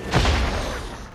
001 falling impact.wav